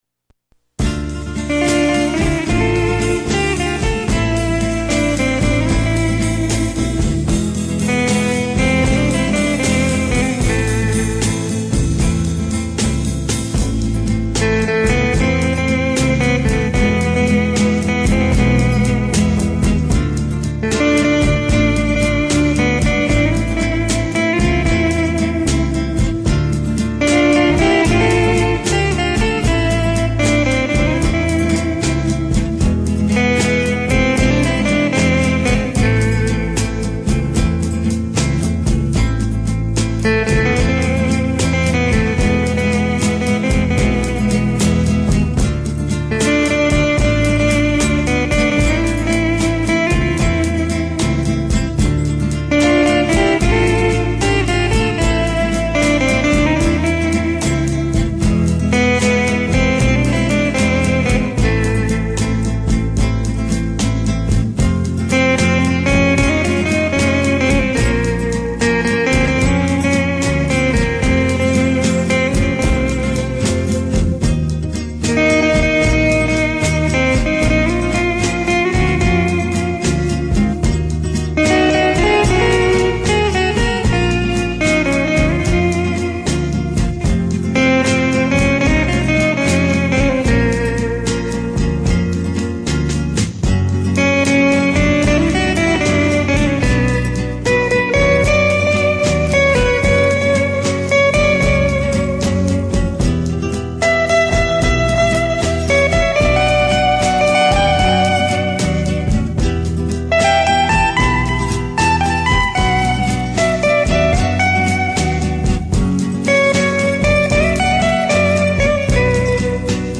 прекрасную меланхоличную и очень навязчивую мелодию